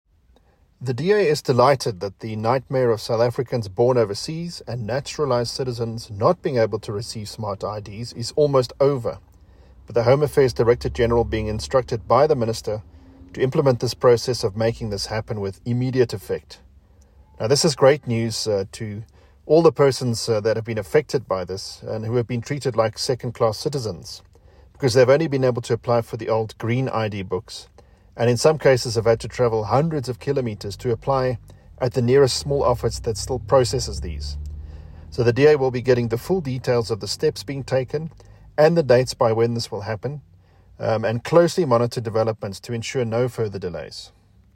soundbite by Adrian Roos MP.